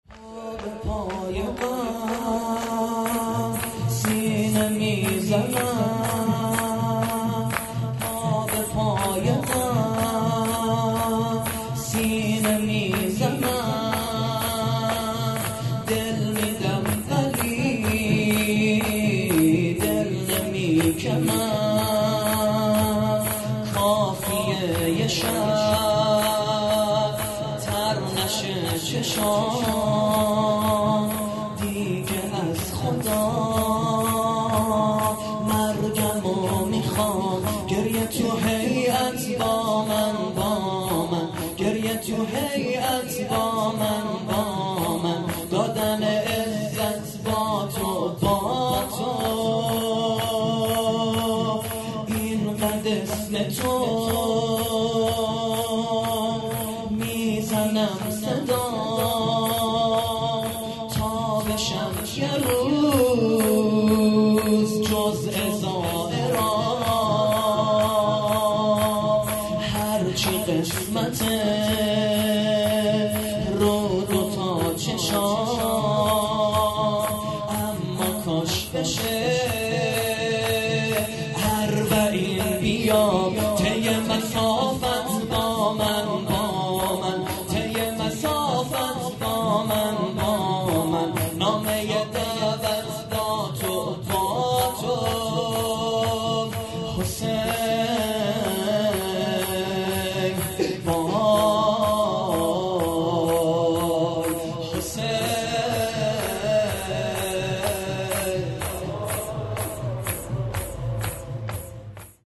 ◼عزاداری دهه اول محرم - ۱۳۹۹/۶/۴